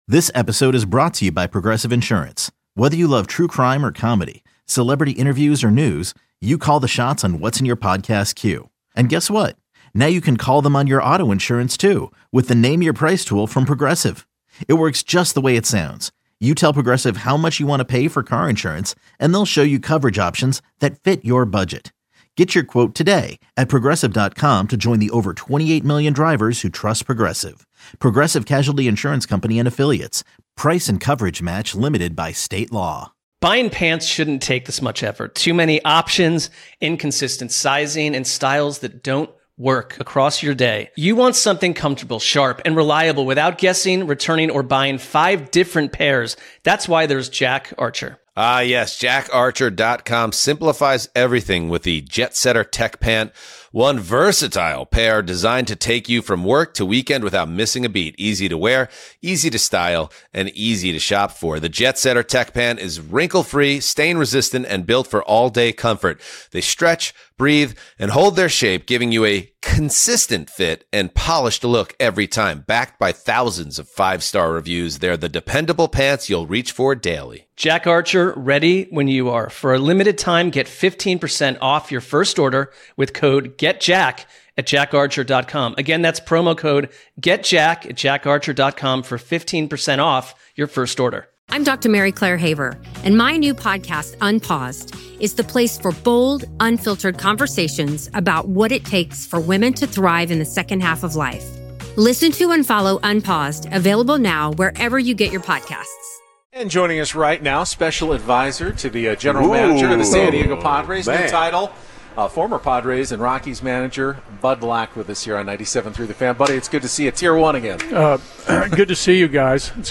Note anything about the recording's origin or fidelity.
LIVE at Spring Training